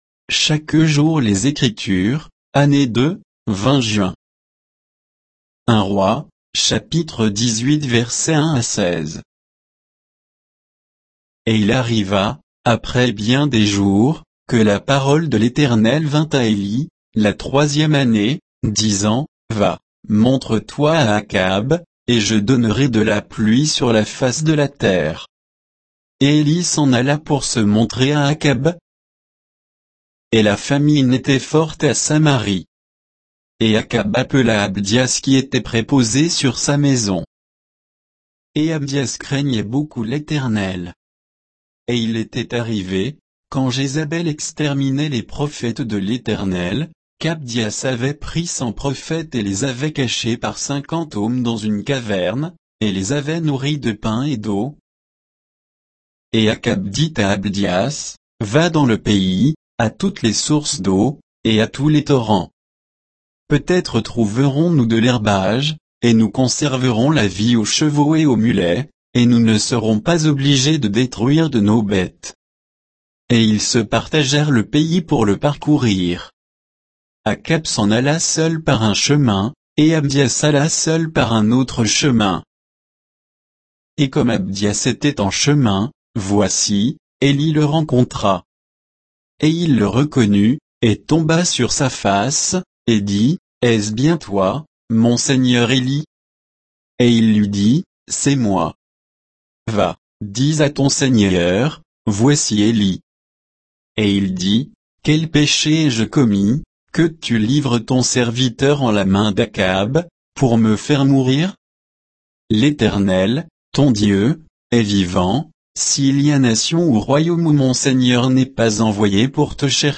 Méditation quoditienne de Chaque jour les Écritures sur 1 Rois 18, 1 à 16